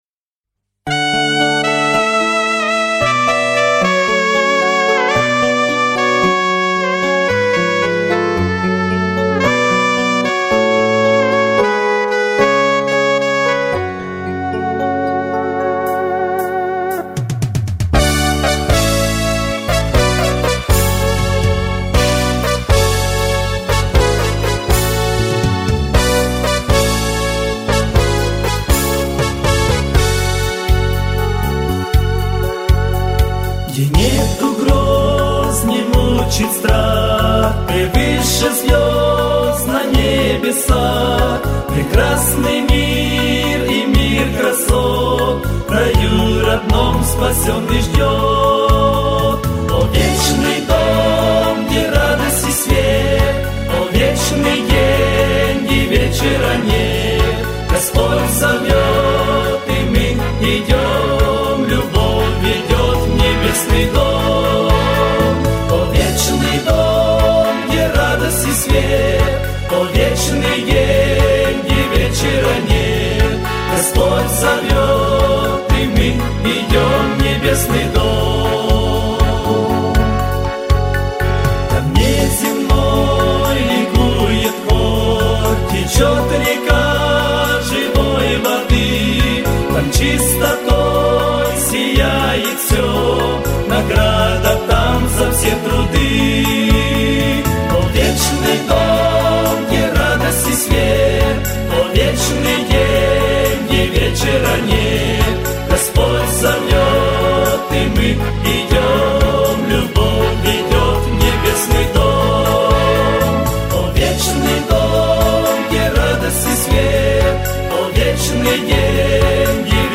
706 просмотров 1173 прослушивания 157 скачиваний BPM: 75